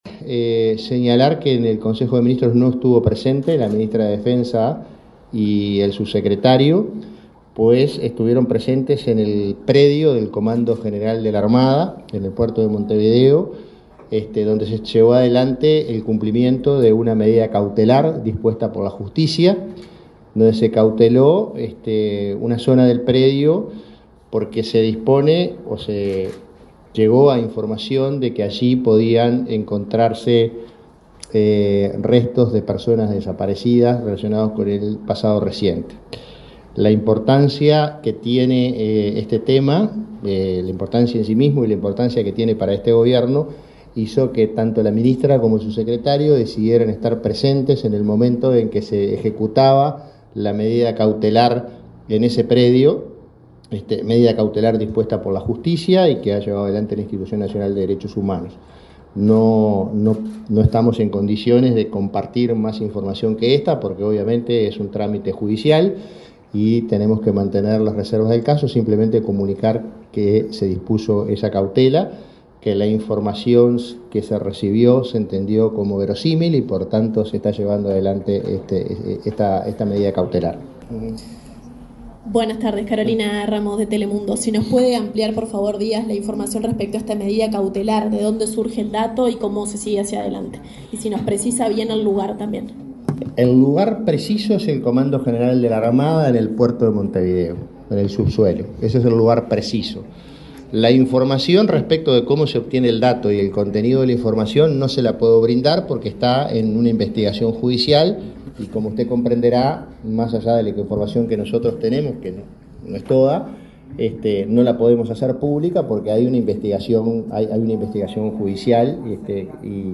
Luego del Consejo de Ministros de este martes 22, el prosecretario de Presidencia, Jorge Díaz, informó a la prensa acerca de la ejecución, por parte